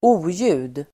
Uttal: [²'o:ju:d]